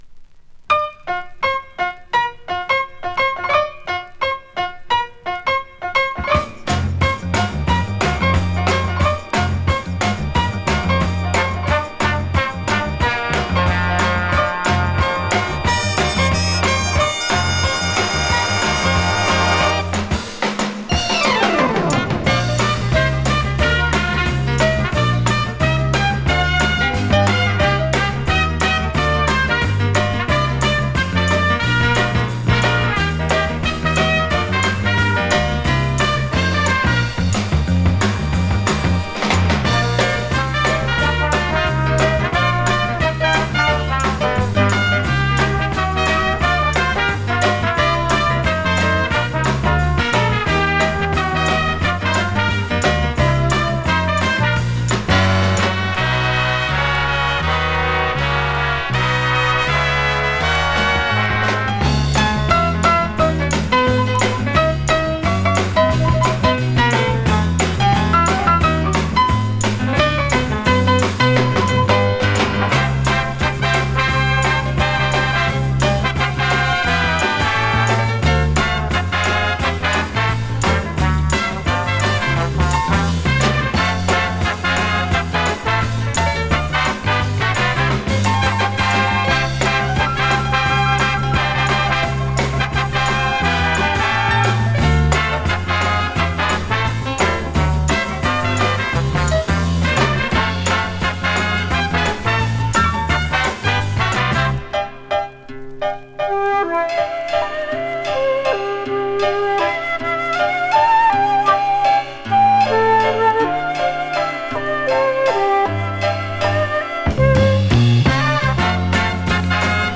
Сегодня получил подарок - оцифровали Новогоднюю пластинку 75-го года, не игранную в качестве 192/32 (битрейт 6000) - звучит как новая пластинка.